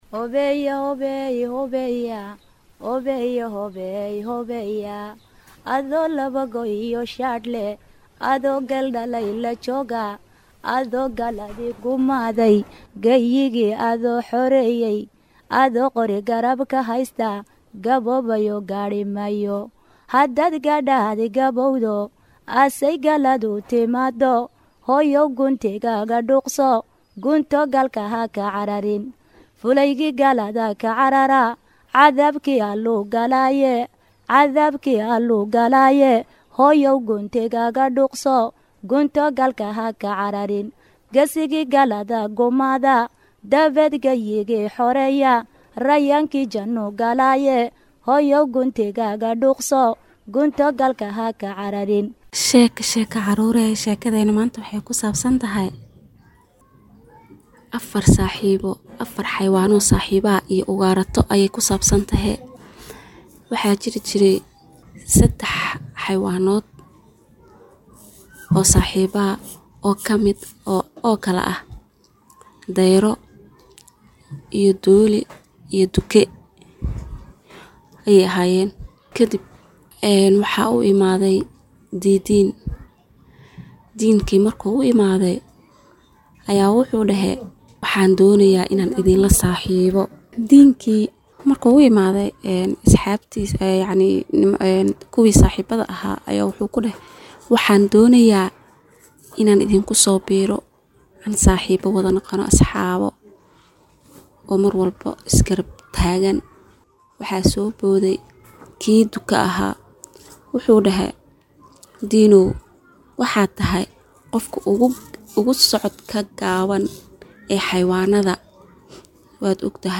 Barnaamijka Tarbiyada Caruurta ee Jimco weliba ka baxa warbaahinta Islaamiga ah ee Al-Furqaan, waa barnaamij tarbiyo iyo barbaarin oo ku socda caruurta iyo waalidiintooda, waxaana xubnaha ku baxa kamid ah xubin loogu magacdaray Sheeko-Xariir, oo ay soo jeedinayaan hooyooyinka Soomaaliyeed.